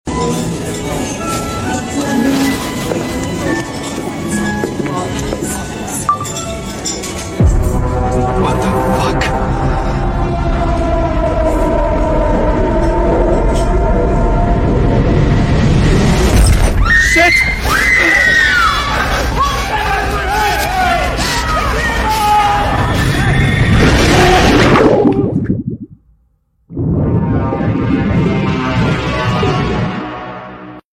A big Scary Monster in sound effects free download
A big Scary Monster in water